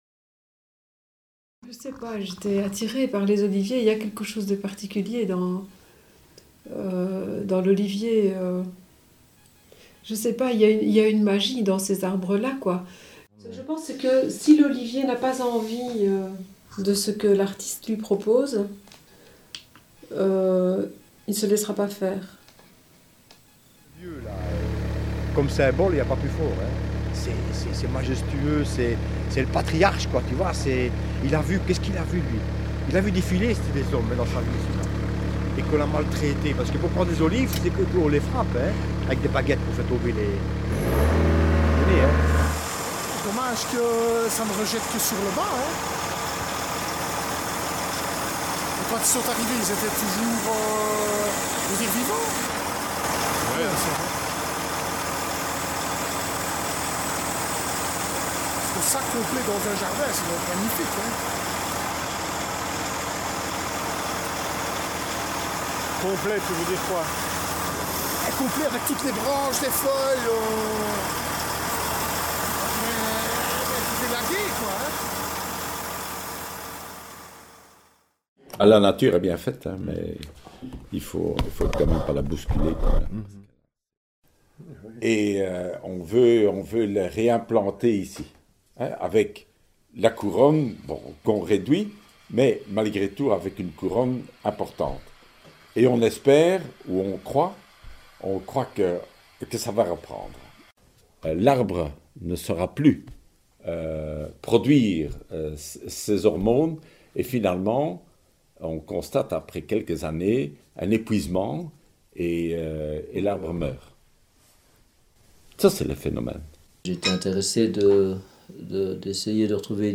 Aragón – Espagne
Extraits entretiens les oliviers
extraits-entretiens-les-oliviers.mp3